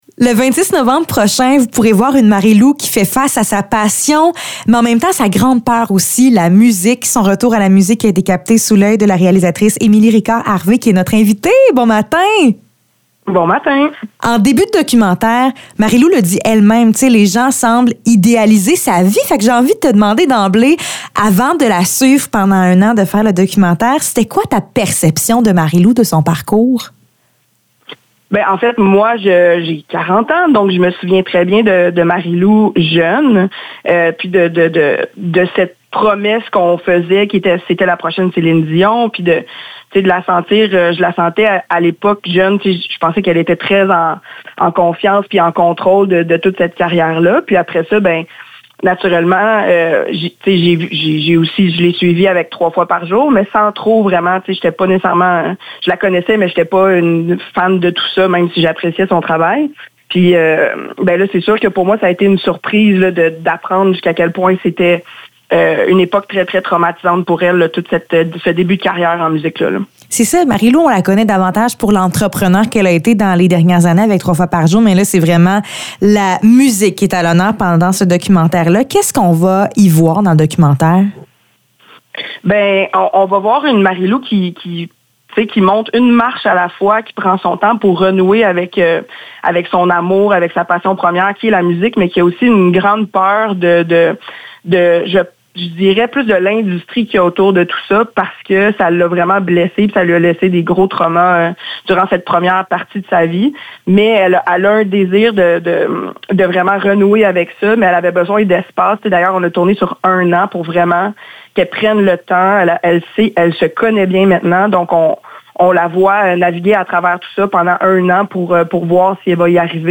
Entrevue pour le documentaire Marilou face à la musique